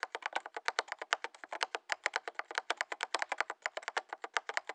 SFX_Typing_04.wav